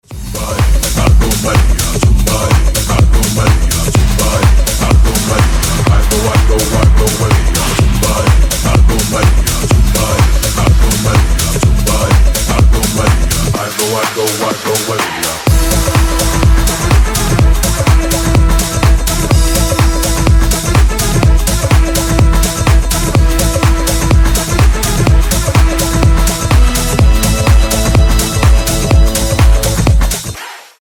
• Качество: 320, Stereo
мужской голос
качающие
африканские